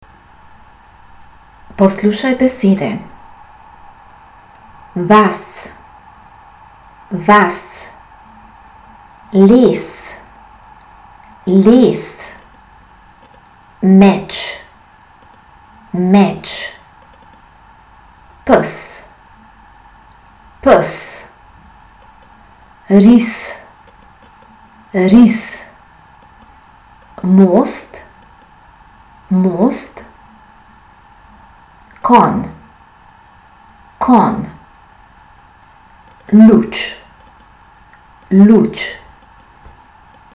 Poslušaj izgovorjene besede.
Pozoren bodi tudi na to, kako široki/ozki so.